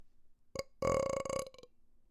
男子打嗝打嗝
描述：男人打嗝
标签： 倍儿清 打嗝
声道立体声